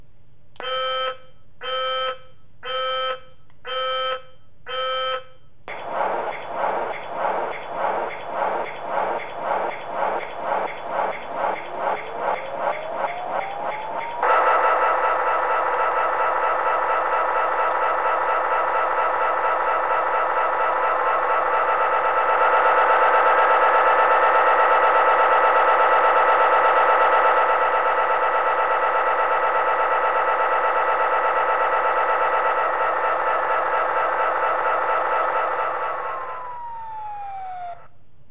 The soundcards provide a big variety of heavy diesel locomotive sounds from Britain, North America and Australasia.
EMD 645